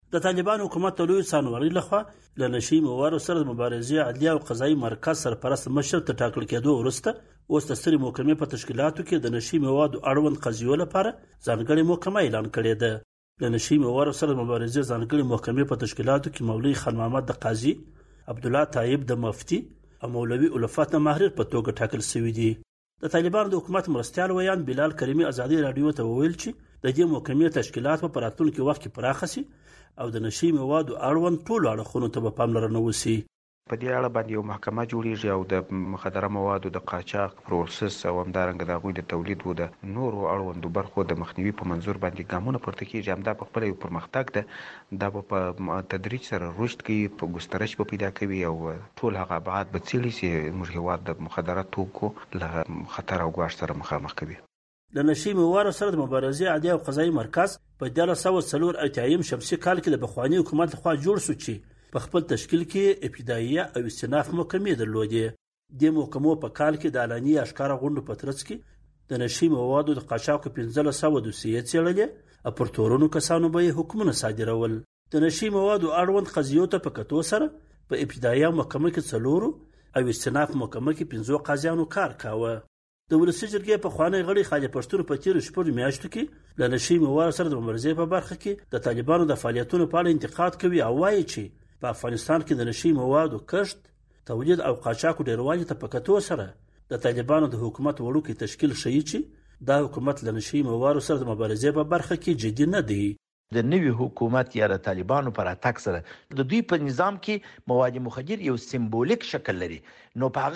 د نشه موادو سره د مبارزې د محکمې راپور